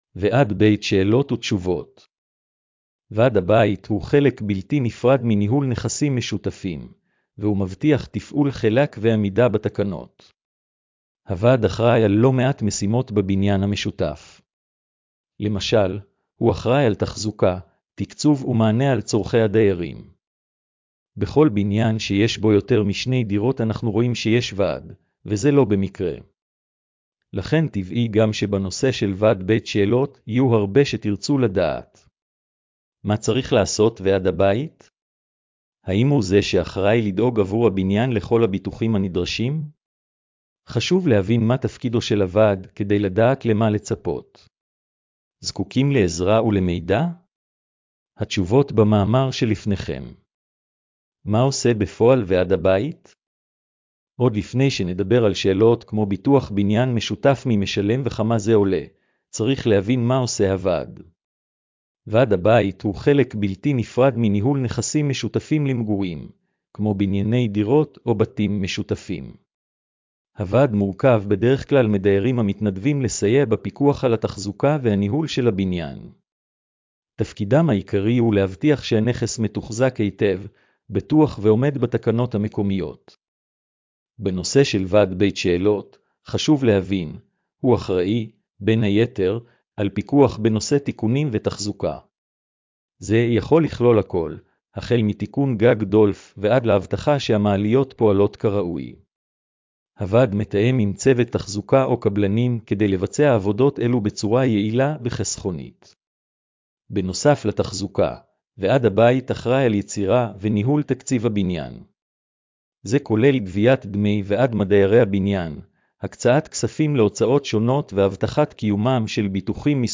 הקראת המאמר לבעלי מוגבלויות: